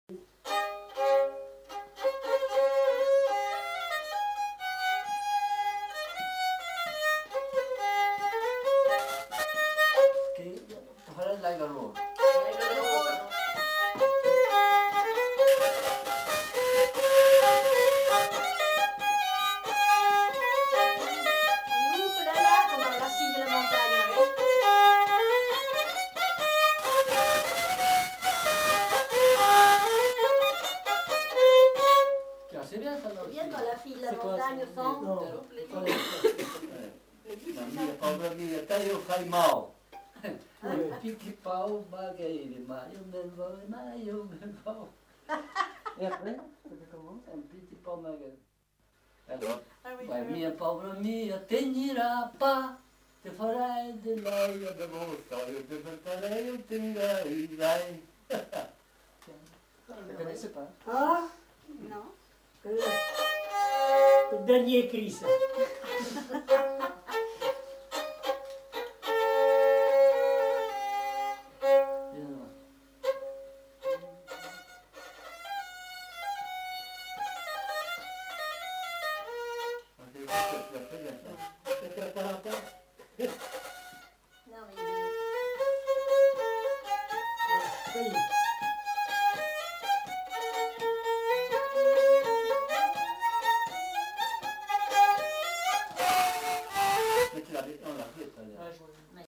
Aire culturelle : Limousin
Lieu : Lacombe (lieu-dit)
Genre : morceau instrumental
Instrument de musique : violon
Danse : bourrée
Notes consultables : Suivi de plusieurs bribes sans suite.